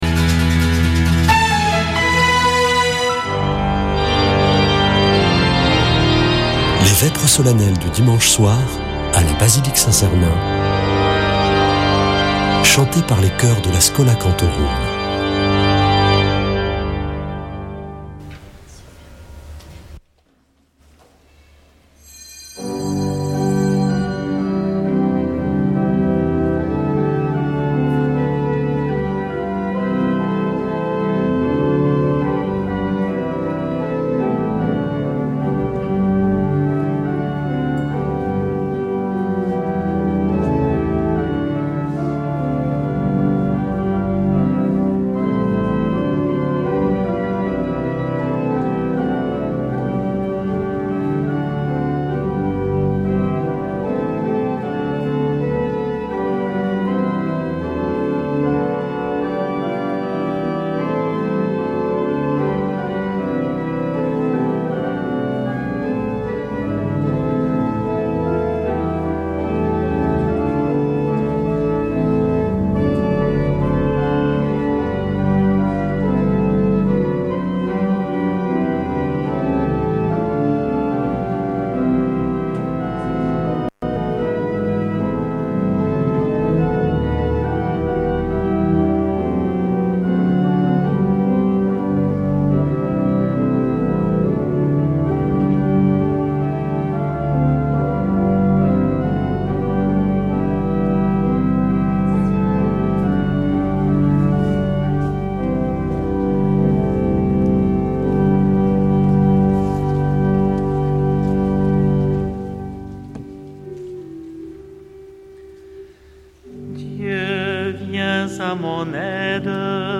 Vêpres de Saint Sernin du 23 nov.